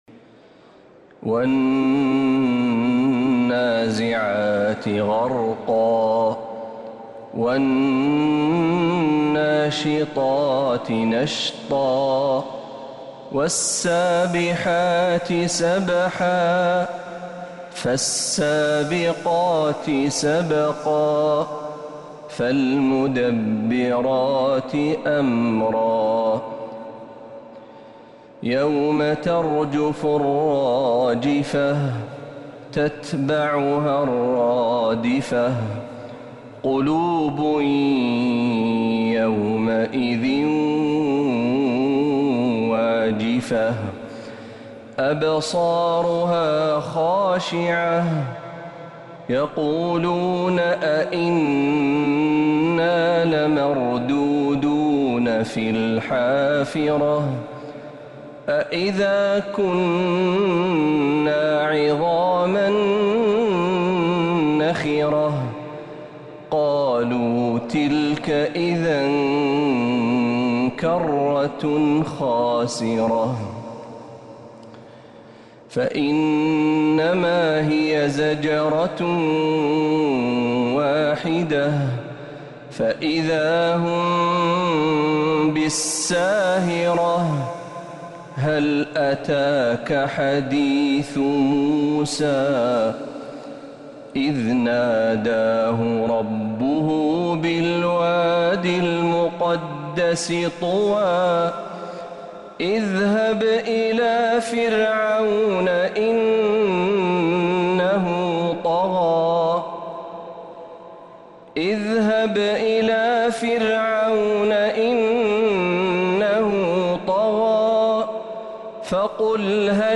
سورة النازعات | رجب 1447هـ > السور المكتملة للشيخ محمد برهجي من الحرم النبوي 🕌 > السور المكتملة 🕌 > المزيد - تلاوات الحرمين